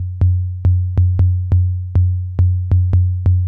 SINE BASS -L.wav